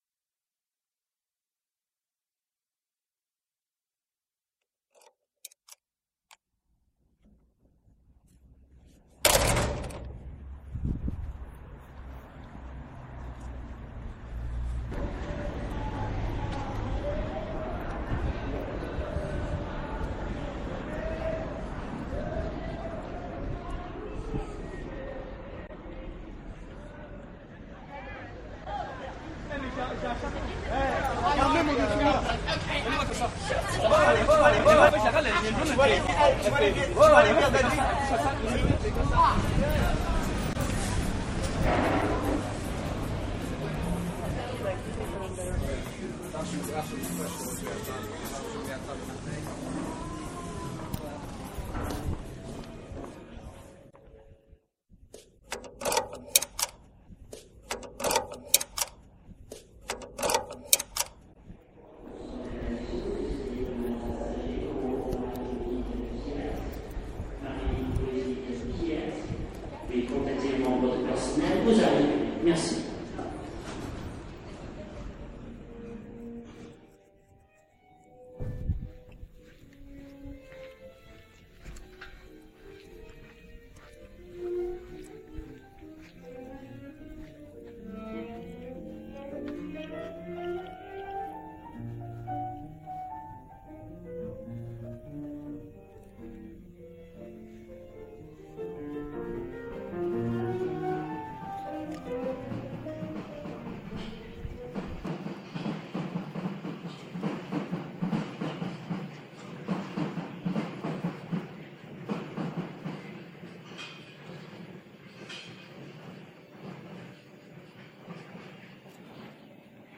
international driving project and live sound performance
along these routes people are invited to contribute soundscape recordings of their local environments via mobile phone technology and the internet.
these sounds form the substance for live performance and installation creating soundtracks intended for car radio and broadcast.
the sounds from one or more remote environment are transmitted live to car, as an evolving sonic environment.